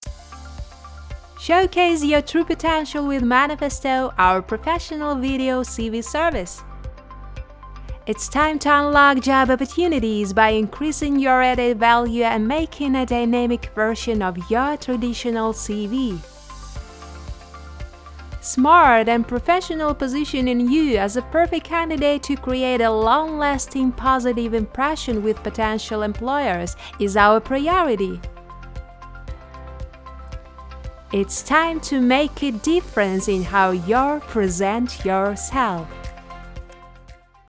Реклама сервиса (ENG)
Пример рекламы сервиса